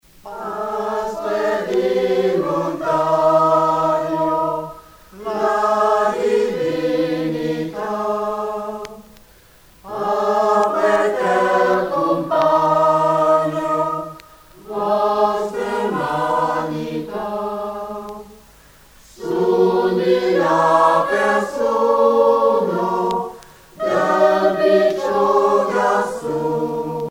circonstance : Noël, Nativité
Chants et danses traditionnelles de Provences
Pièce musicale éditée